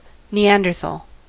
Ääntäminen
IPA : /niːˈæn.dəˌtɑːl/